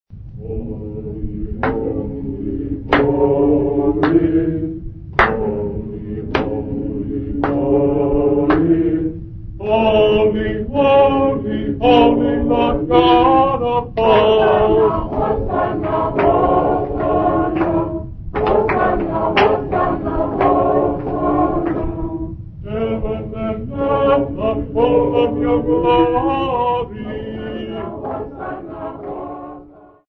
Xhosa composers workshop participants
Folk music
Sacred music
Field recordings
Africa South Africa Zwelitsha sa
Music workshop performance, accompanied by clapping